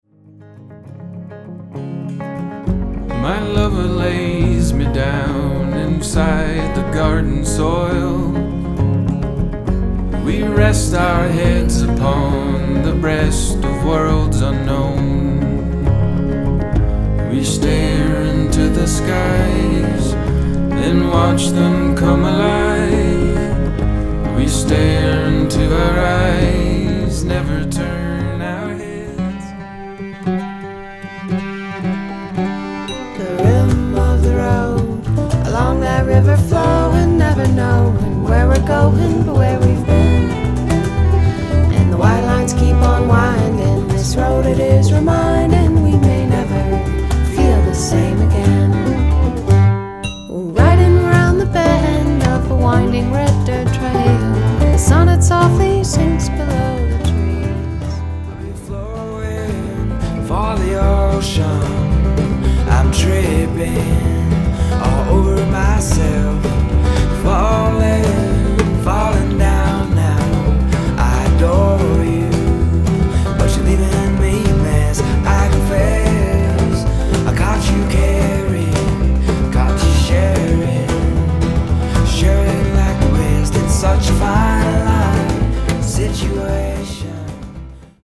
Genre/Style: folk, transcendental-folk